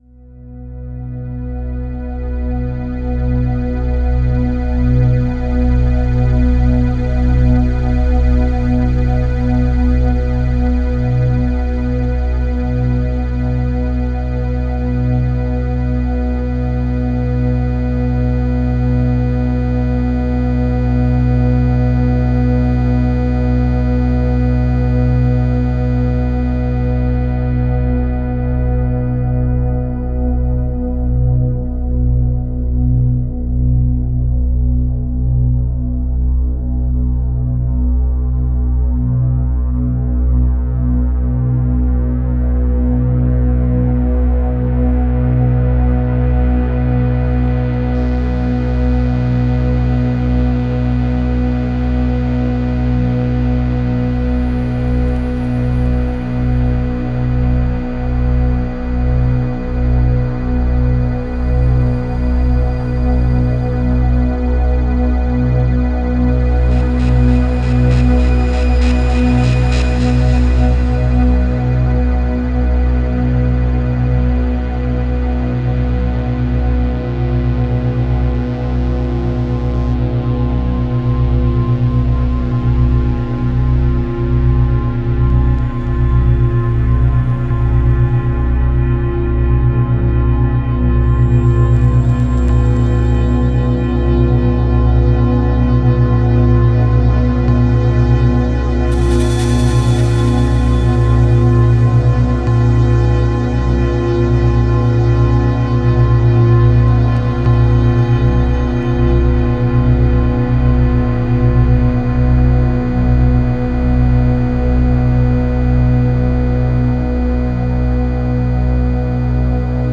CEM I, 2026 Lab stand, soil, living mycelium (Pleurotus Ostreatus), Speakers, electronics. Pied de laboratoire, terre, mycélium vivant (Pleurotus Ostreatus), haut-parleurs, électronique.